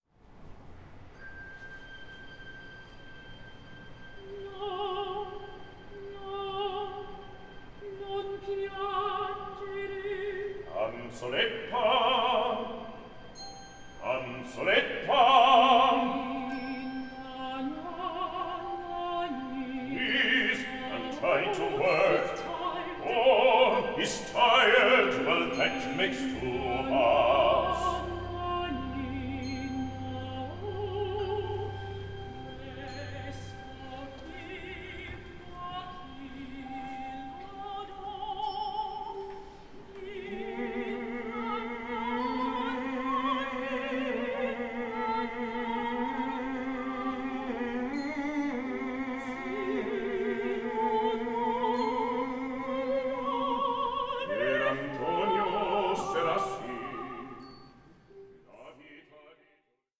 Aria con recitativo a parte